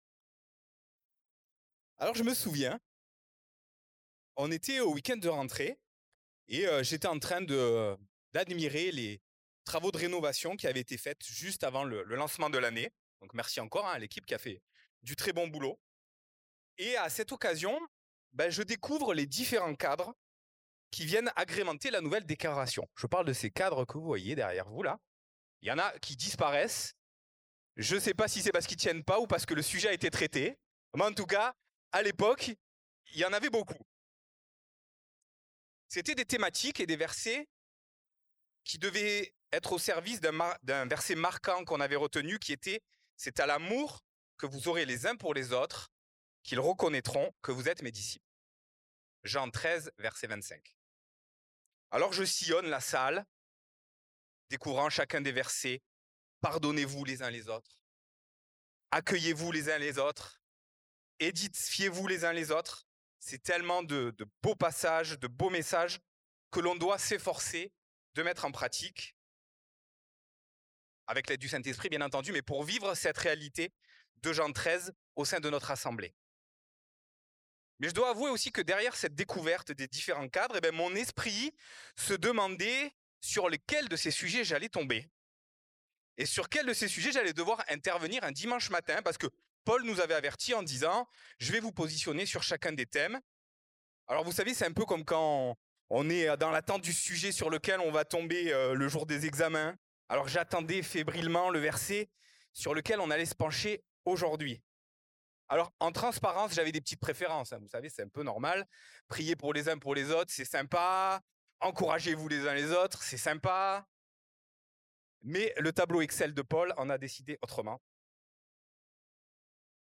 Culte du dimanche 02 novembre 2025